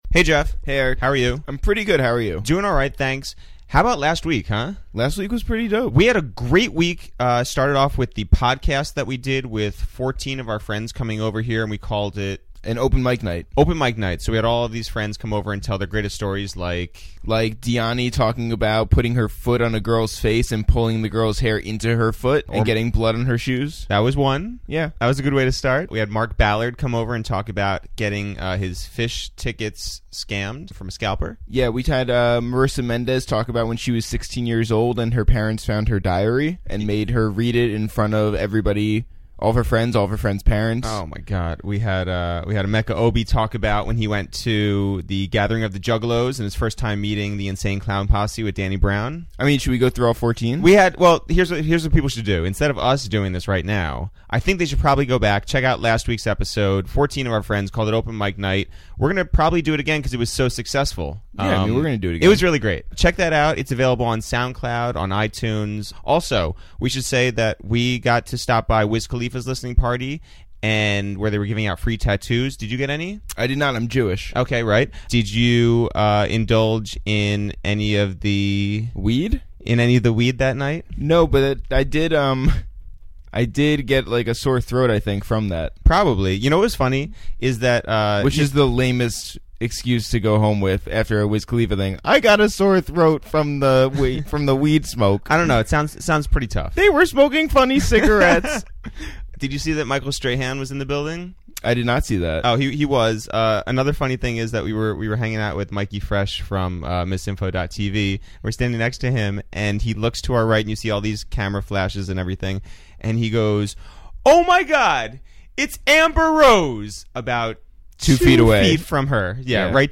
This week, we hosted a live podcast from NYC's famed concert venue SOB's, where we interviewed our good friend DJ Enuff! We spoke on his time with Biggie, the time he first met DJ Khaled, and how Kanye West became a part of Enuff's Heavy Hitter crew.